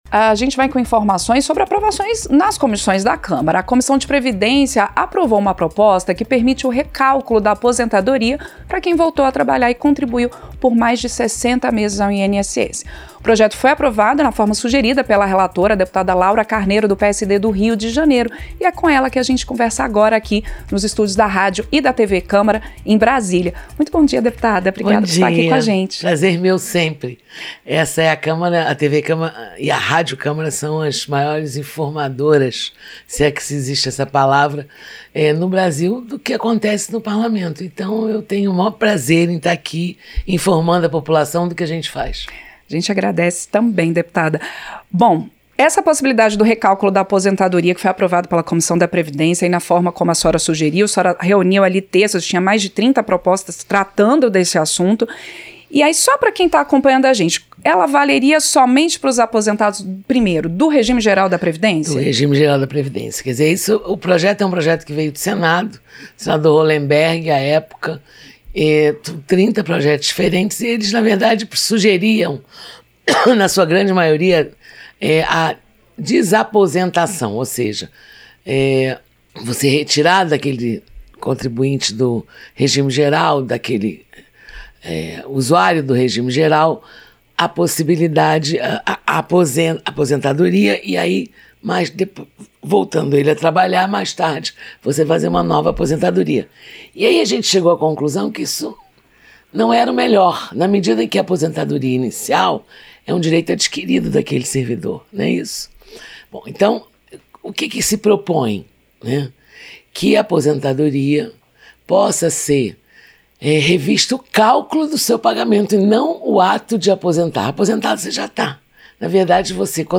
Entrevista - Dep. Laura Carneiro (PSD-RJ)